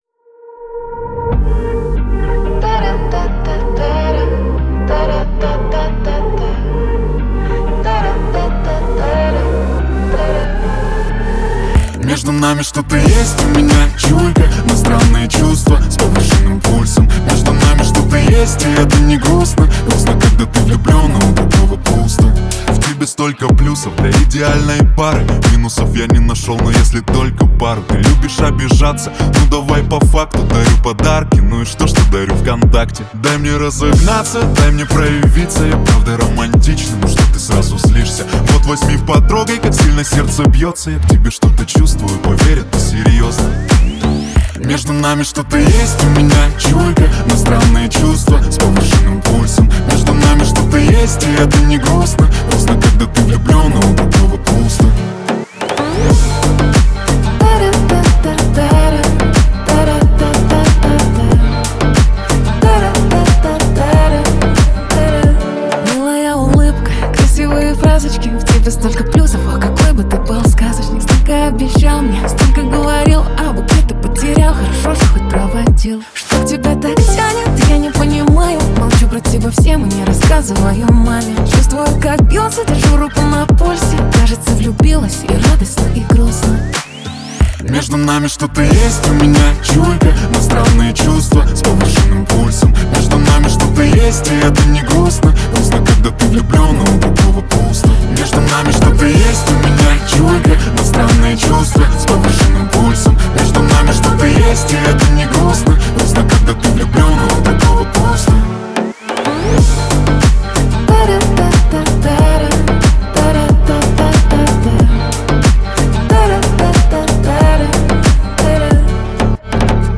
танцевальная музыка